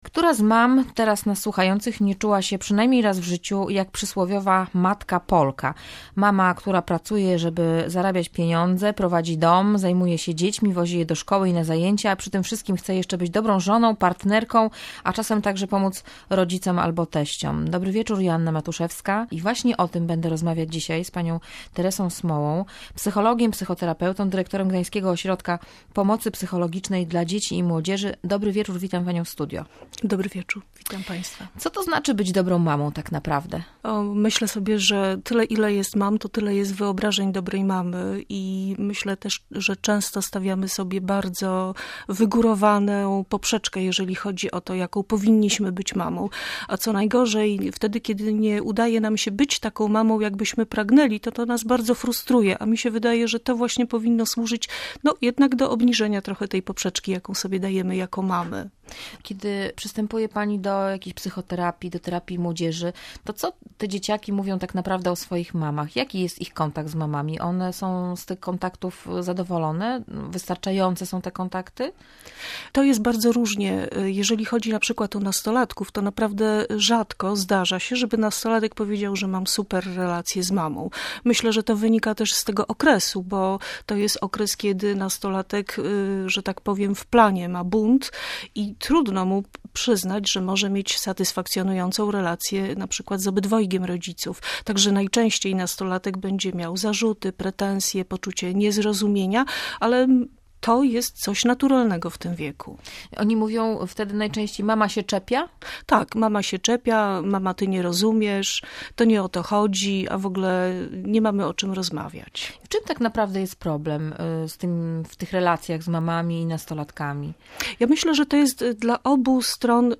Psycholog i psychoterapeutka radziła, żeby nie wyolbrzymiać w domu konfliktów – np. związanych z bałaganem w pokoju.